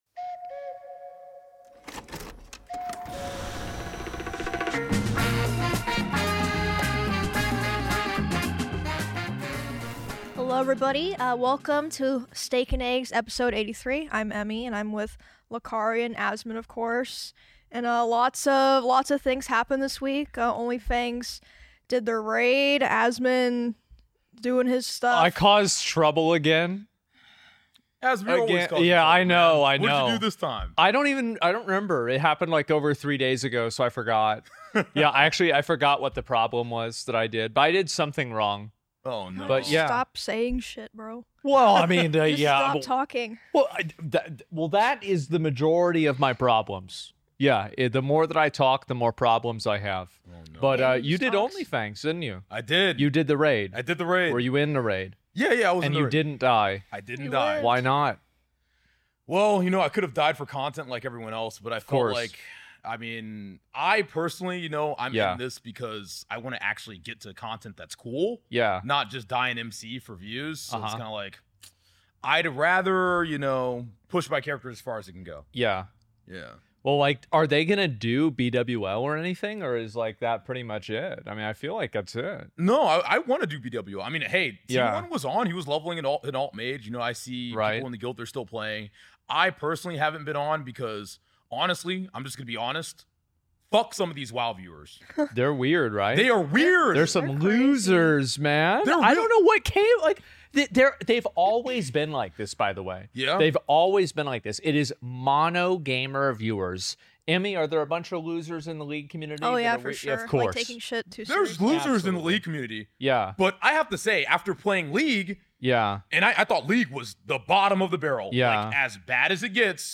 In dieser Dreierkonstellation gibt es nicht nur geballte 1,5 Stunden Fussballtalk, sondern auch einige Lacher!